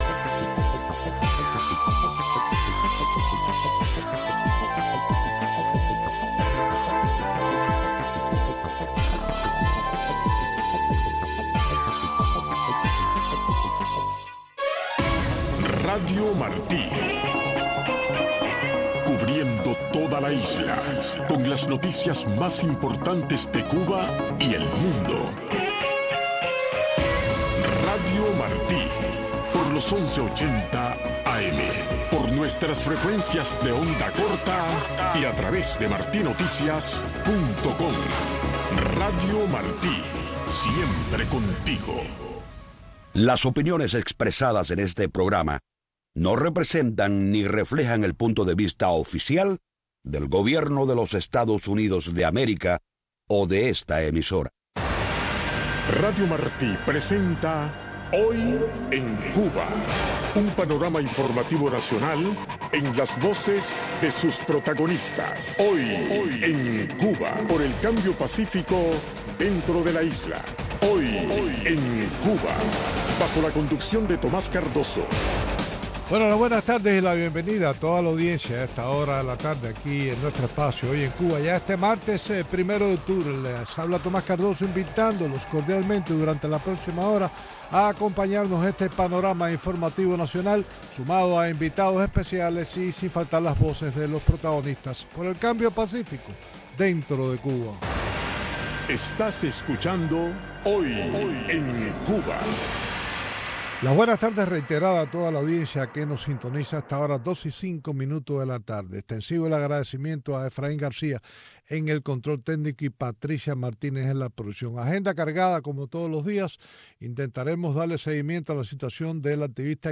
Noticiero de TV Martí